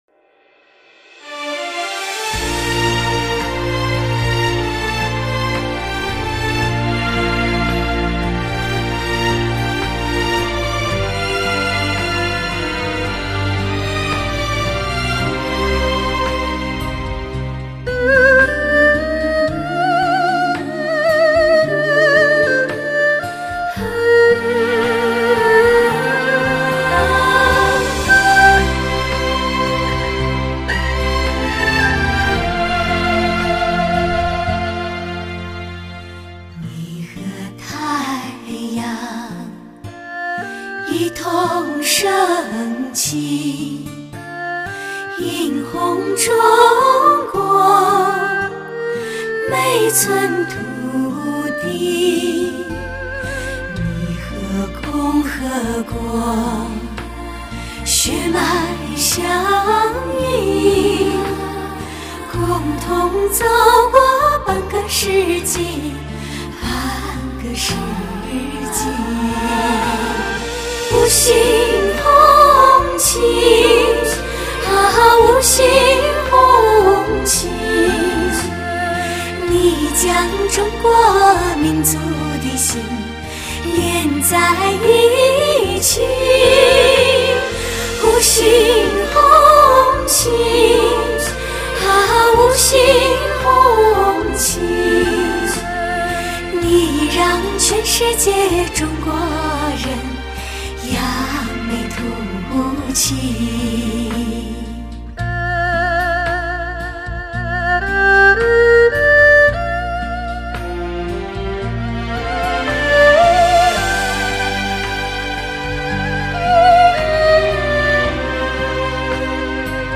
，十大人声典范。